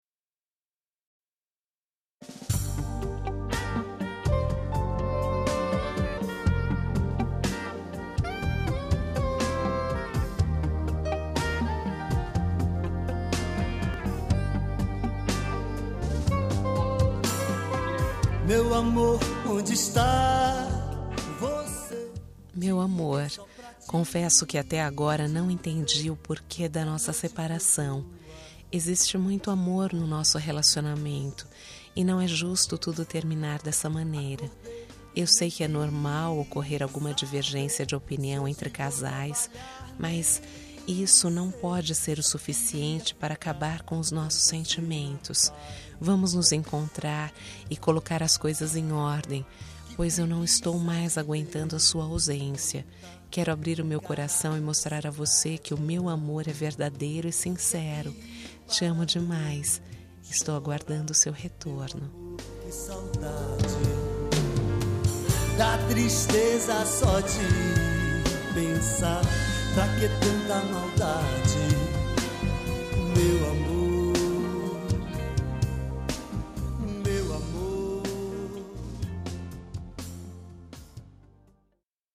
Telemensagem de Reconciliação Romântica – Voz Feminina – Cód: 854